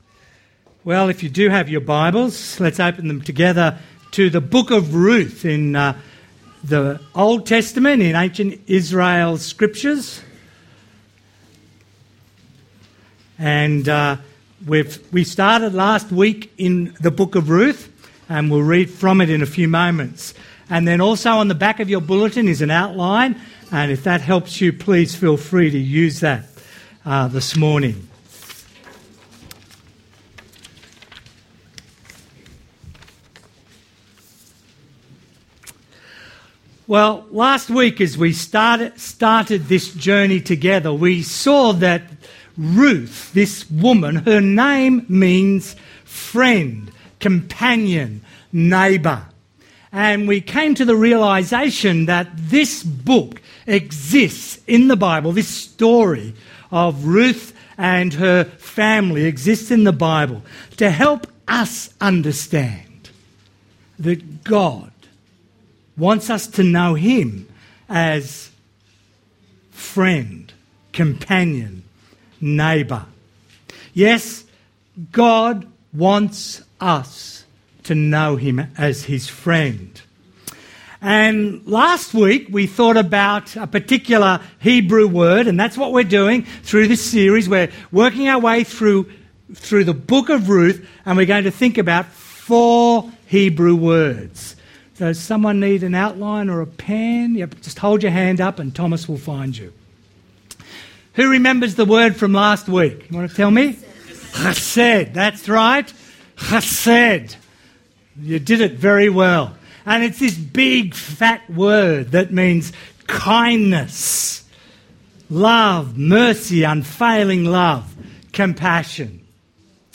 Ruth Listen to the sermon. Categories Sermon